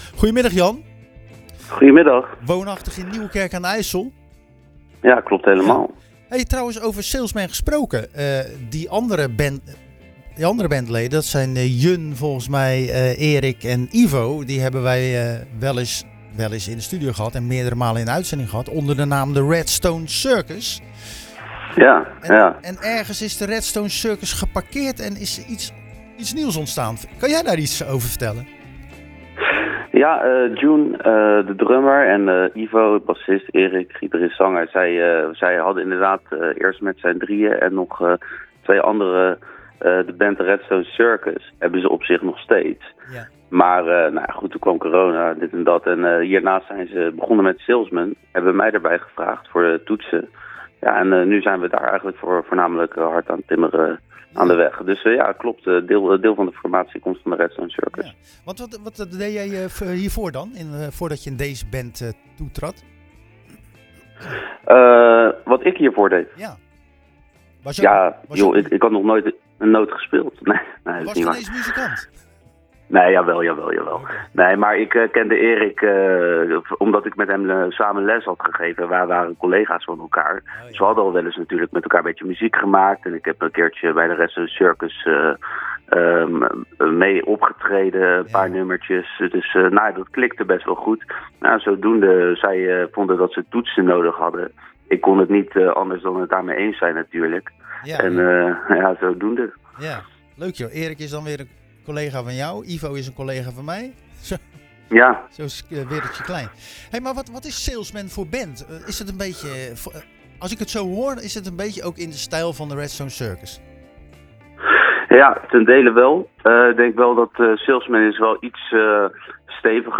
Tijdens de uitzending van Zwaardvis belde we ��n van de bandleden van de Haagse Band Salesmen.